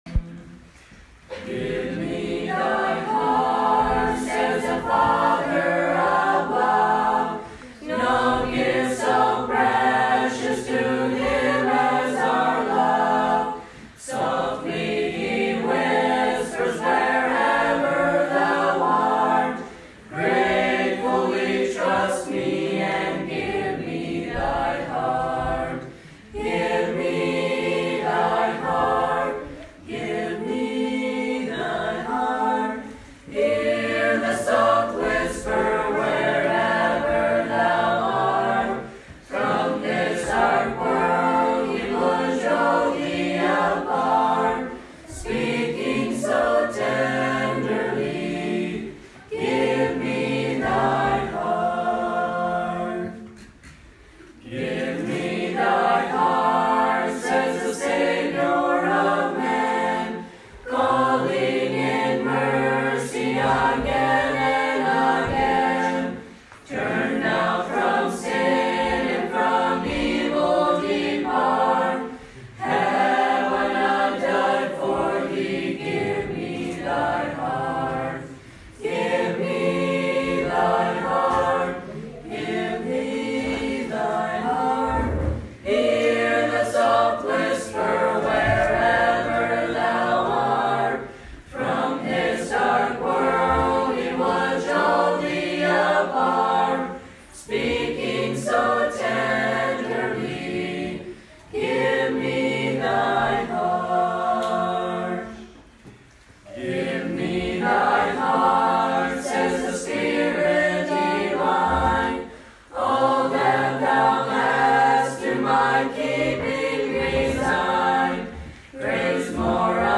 For this week, here's a song from a school in Ukraine.
Give-Me-Thy-Heart-Ukraine-School.mp3